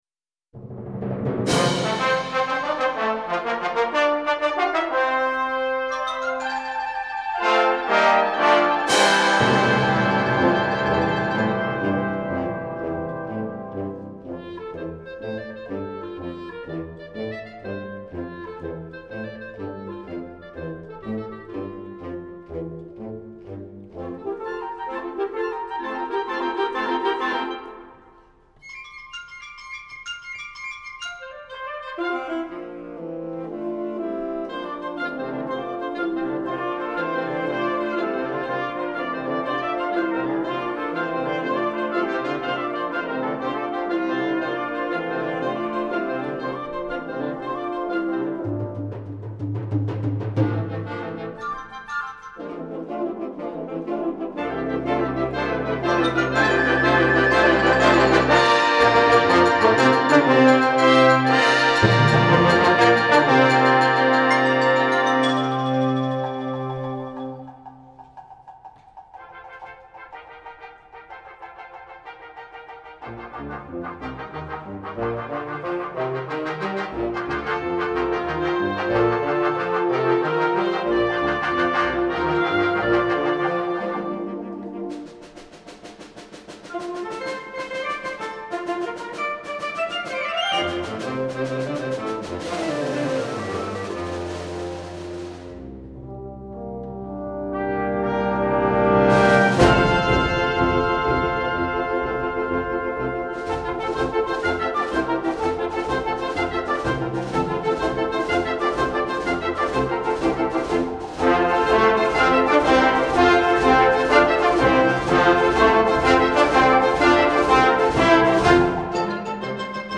Concert Band
Traditional
Both versions, are rollicking, and good humoured.
Rhythmic and fun from beginning to end!